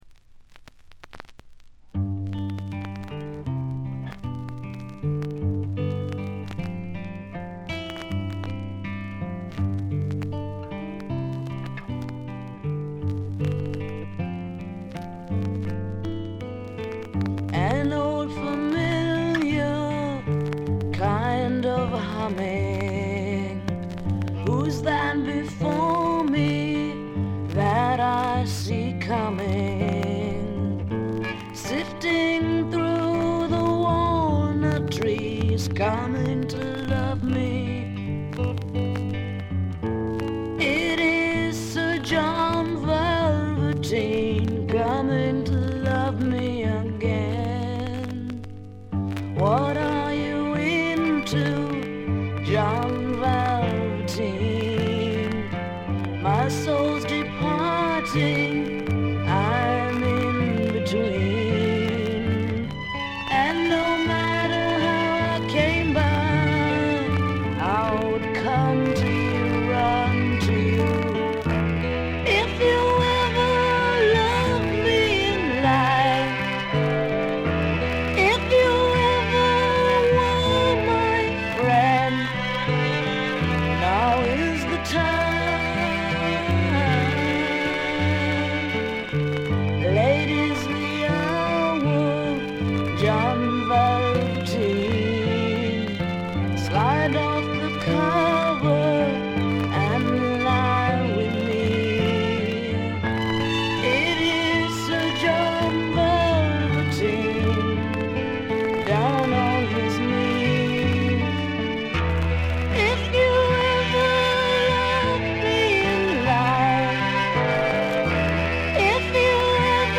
カナダ出身の女性シンガーソングライターが残したサイケ／アシッド・フォークの大傑作です。
試聴曲は現品からの取り込み音源です。